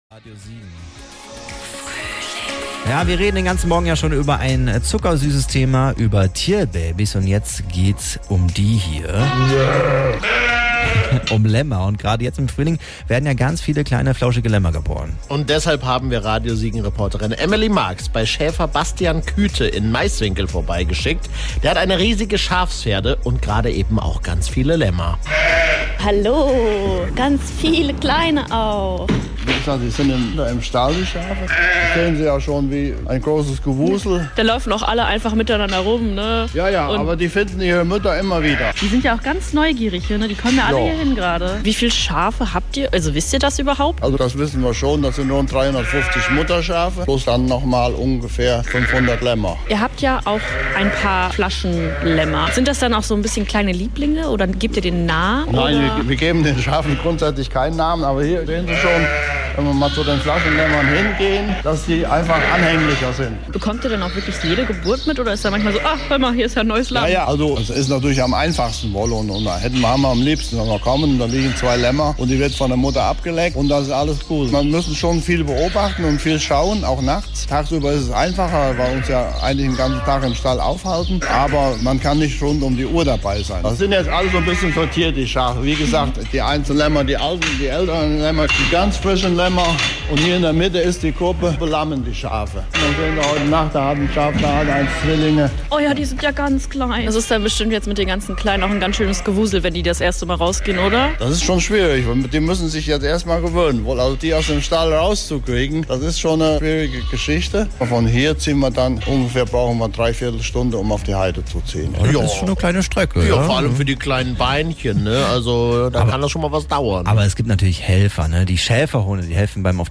Lämmer | Schäferei Küthe in Meiswinkel